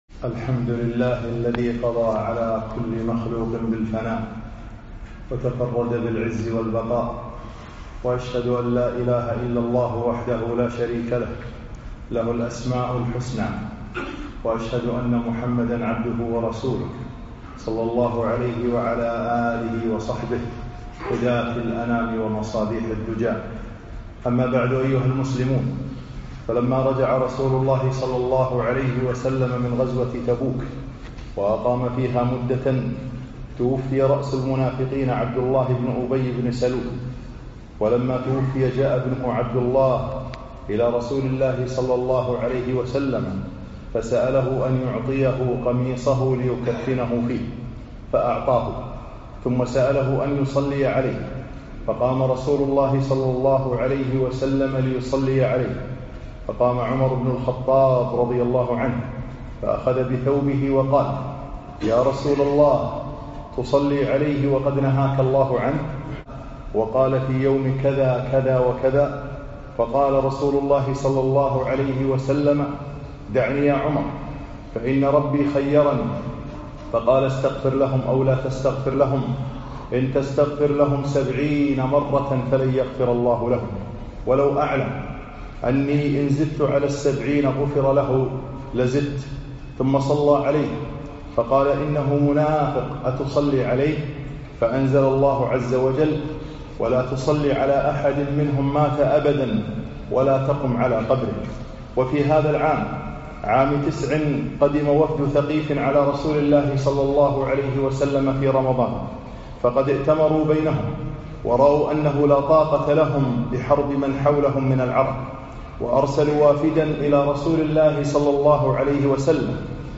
خطب السيرة النبوية 27